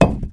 concrete2.wav